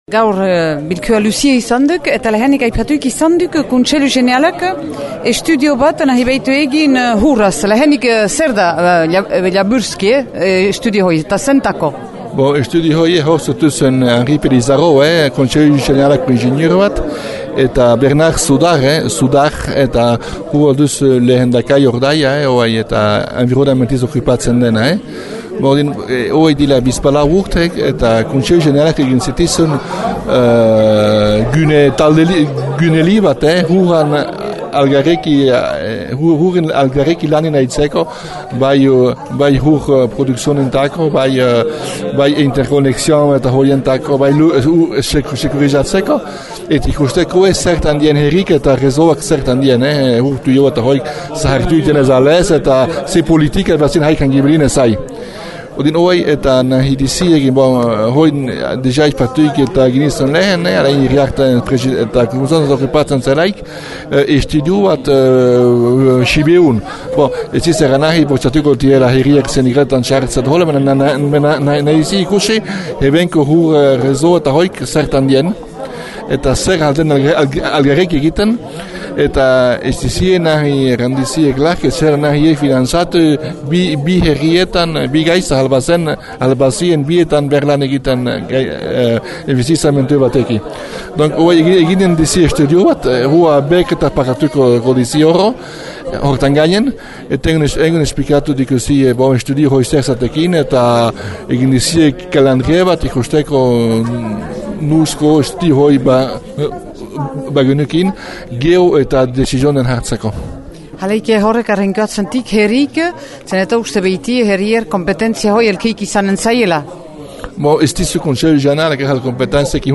Entzün Dominika Boscq, Xiberoa Herri Alkargoako bürüa :